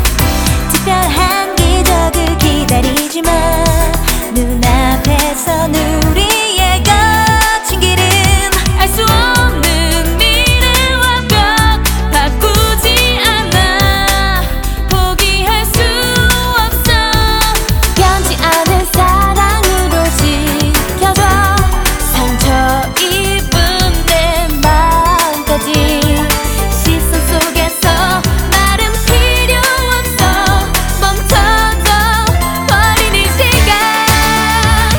Жанр: Танцевальные / Поп / Рок / K-pop